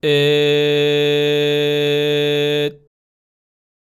喉頭は普段の状態でグーで口